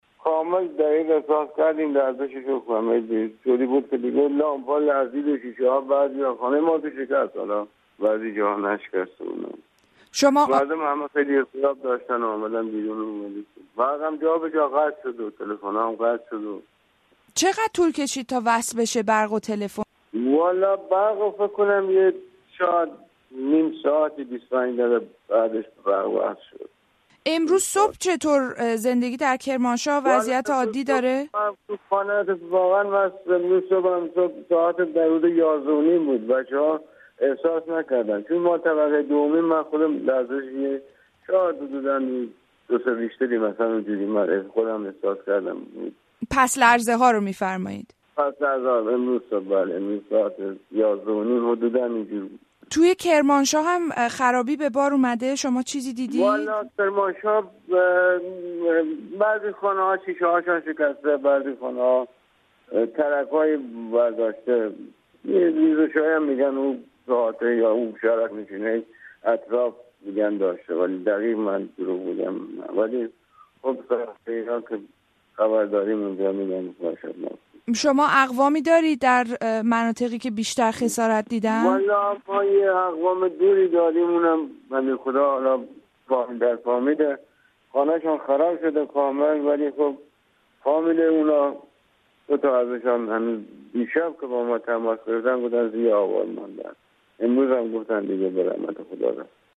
زمین‌لرزه قدرتمندی در ایران بیش از ۴۰۰ کشته برجا گذاشته است. یک شاهد عینی در مورد وضعیت کرمانشاه و واکنش مردم در زمان وقوع زلزله گفتگو کرده است.